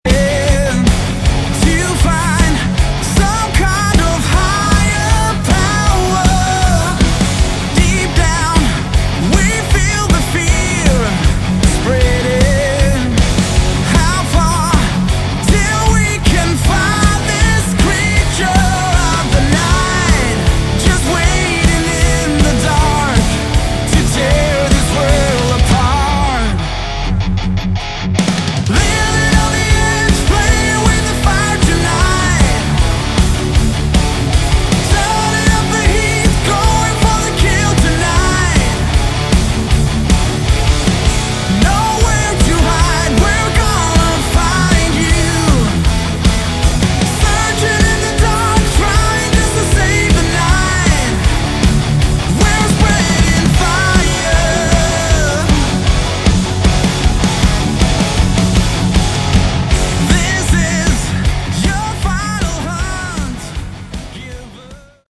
Category: AOR / Melodic Rock
lead vocals
lead guitar
rhythm guitar
keyboards
bass
drums
Sounds like Swedish AOR.